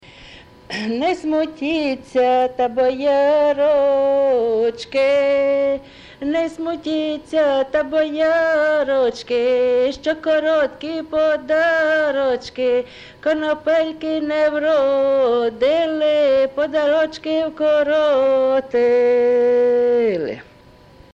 ЖанрВесільні
Місце записус. Яблунівка, Костянтинівський (Краматорський) район, Донецька обл., Україна, Слобожанщина